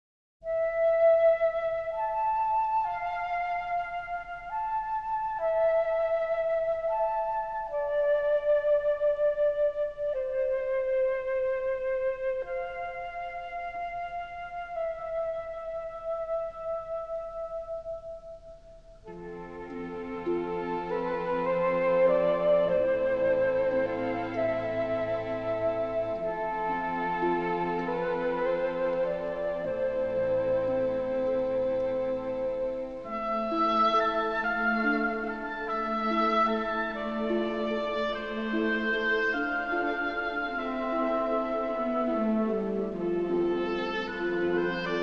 lovely, melodic scoring
newly remastered from the best possible stereo sources